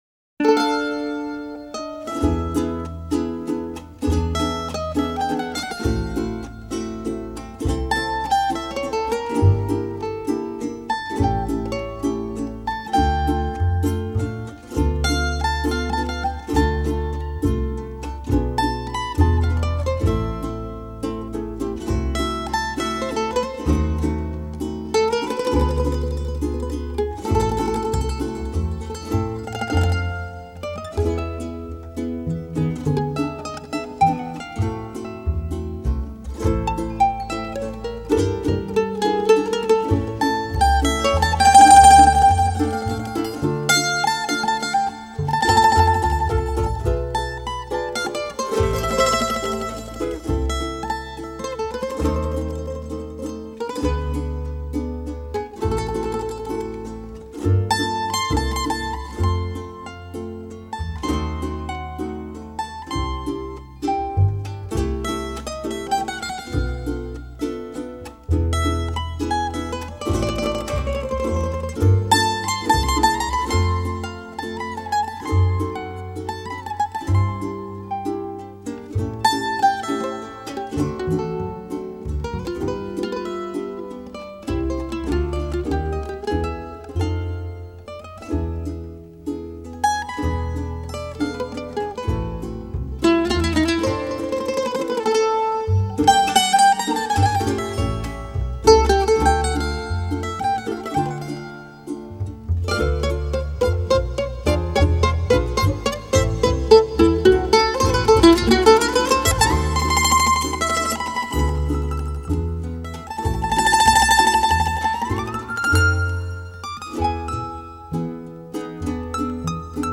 Música latina
Pars Today- La música de América Latina.